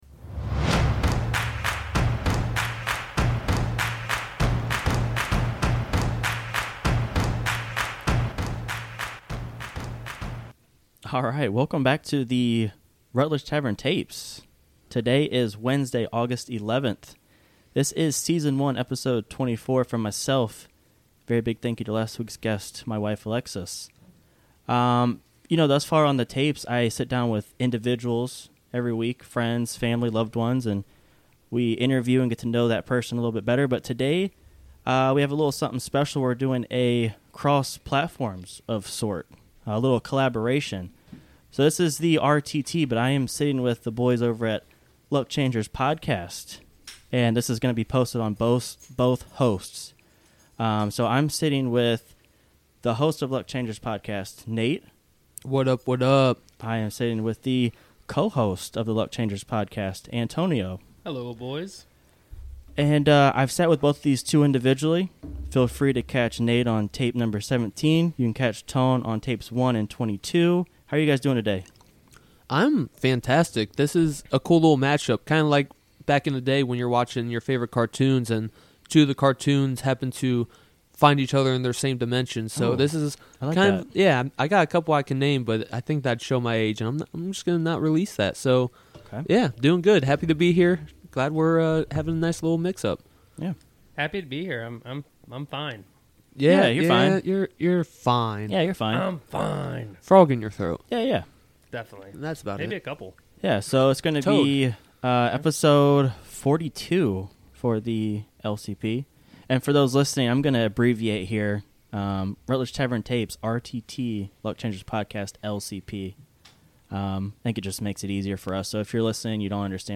This week the boys meet up at the Rutledge Tavern.
Sitting down for an interview talking all things Luck Changers Podcast.